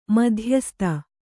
♪ madhyasta